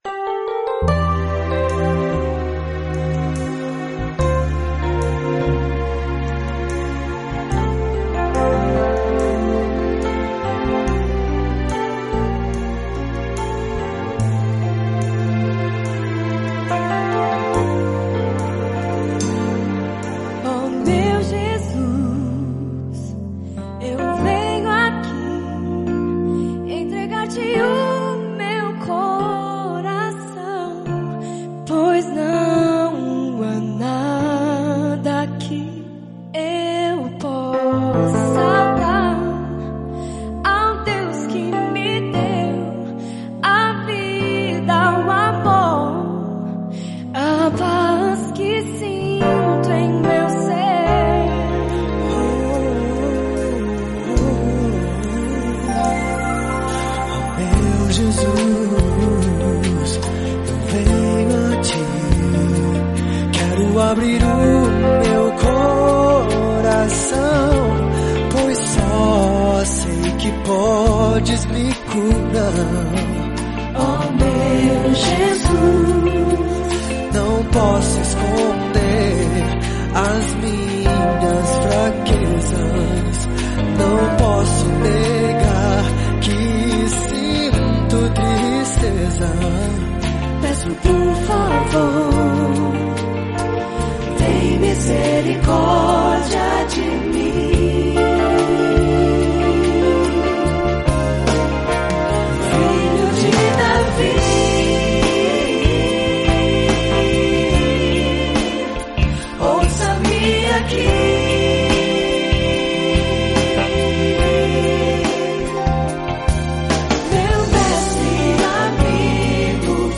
Em diversas oportunidades, participei, ao vivo, de programas da 'Rádio Você', emissora que fazia parte do grupo jornalístico 'O Liberal', de nossa cidade, Americana.